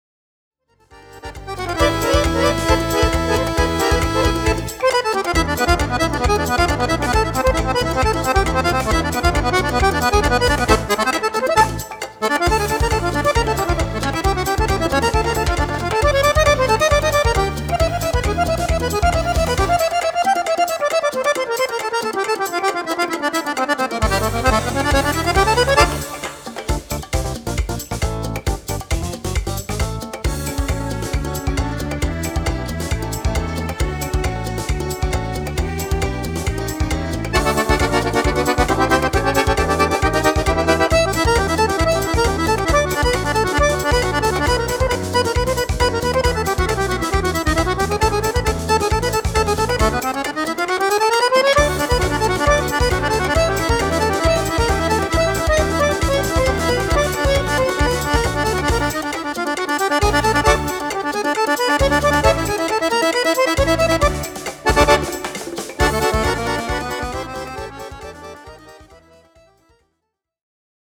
Samba
Fisarmonica
Strumento Fisarmonica (e Orchestra)